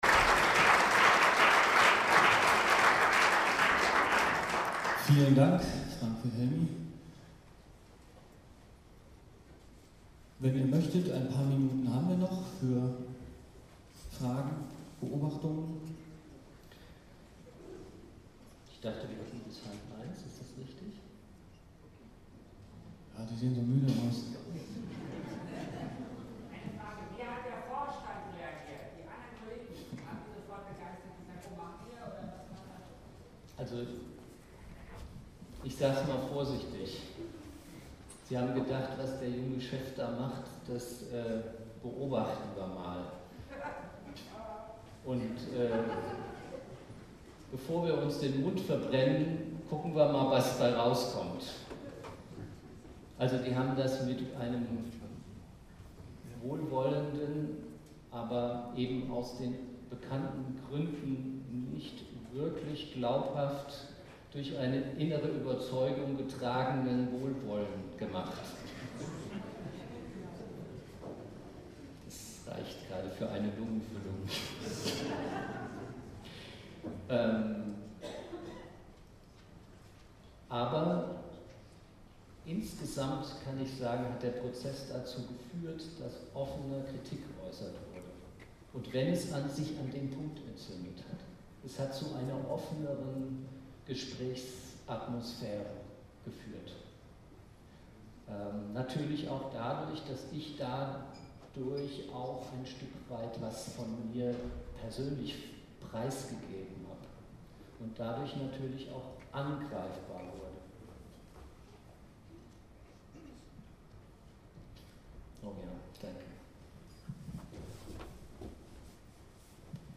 Vortrag
RAD-Tagung 2010